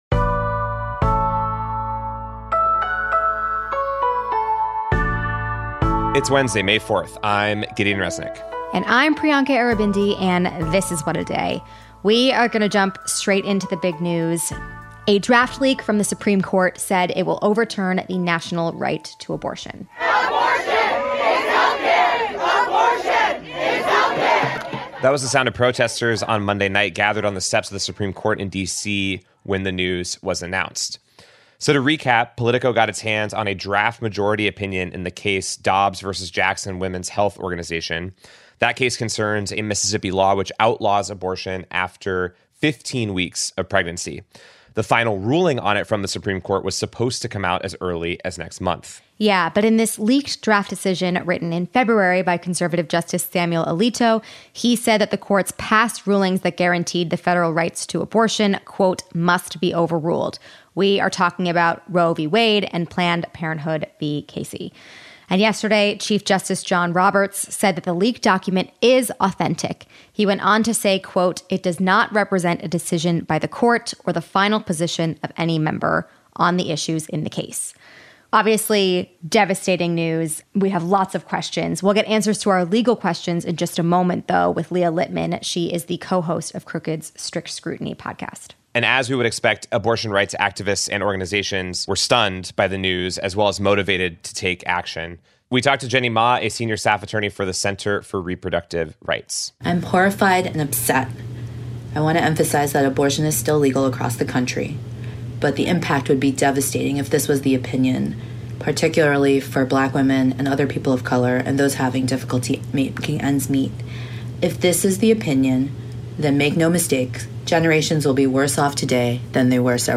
joins us to answer our legal questions about what this could mean for abortion access nationwide and future Supreme Court rulings. And in headlines: Russian forces stormed Mariupol’s embattled steel mill, parts of India and Pakistan are in the midst of a brutal heatwave, and the federal go